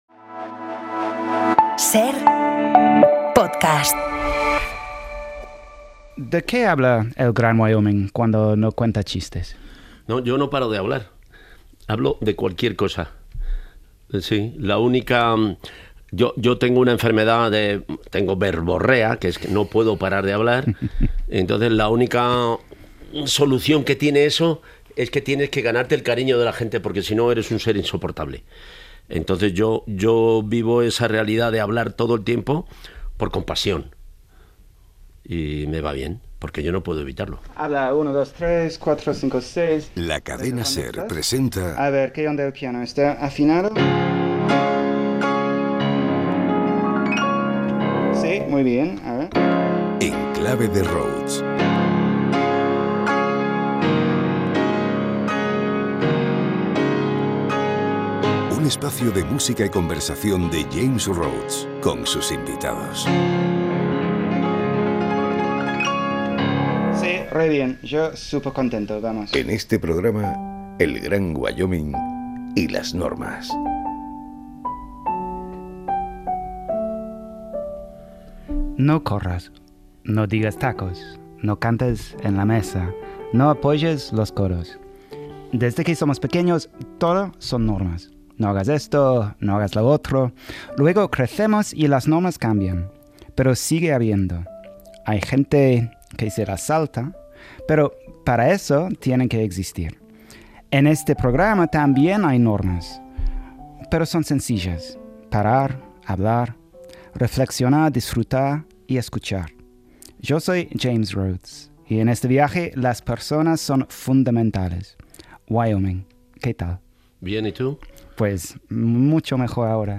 En este programa de En clave de Rhodes, James Rhodes y El Gran Wyoming hablan sobre la vida, el humor, el amor y la valentía ideológica, entre otras cosas. Todo en el marco de una charla distendida en la que el eje central son las normas y como estas, a veces de forma más clara y a veces menos, articulan nuestra vida. Como en cada programa, la música sirve de engranaje a una charla íntima y cercana entre ambos personajes públicos.